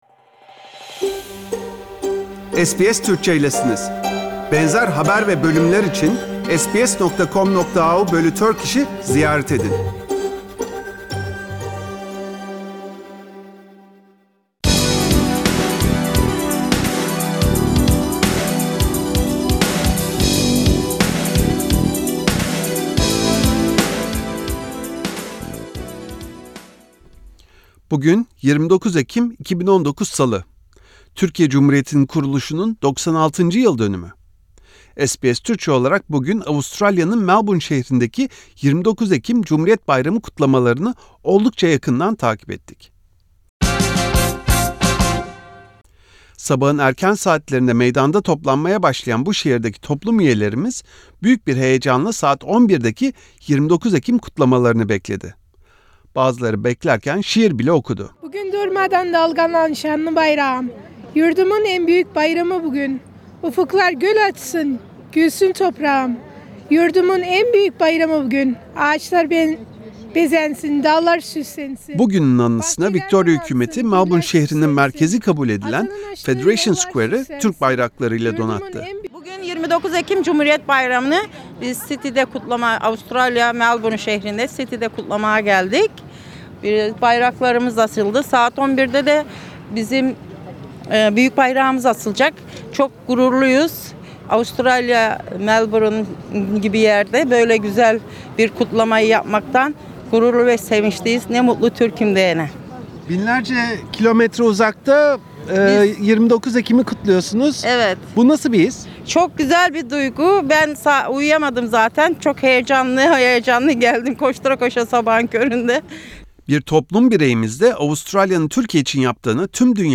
Melbourne'daki 29 Ekim töreni 2019 Source: SBS